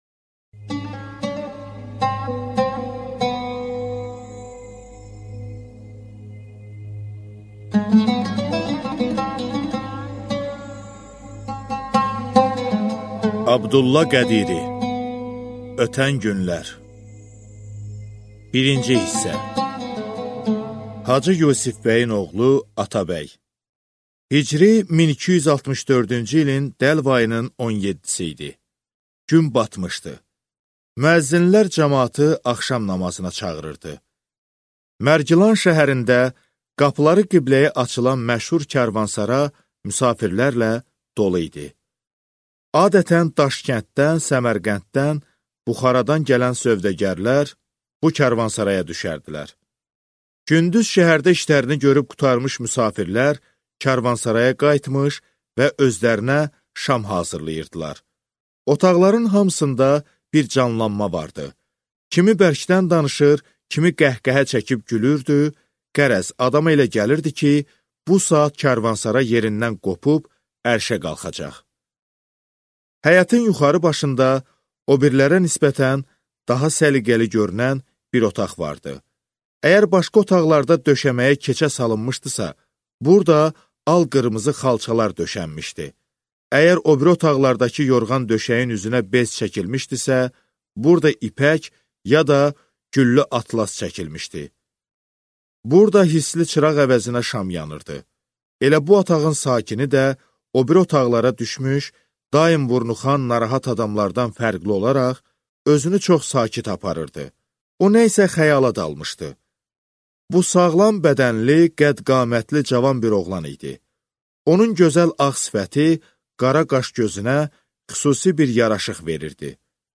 Аудиокнига Ötən günlər | Библиотека аудиокниг
Aудиокнига Ötən günlər Автор Абдулла Кадыри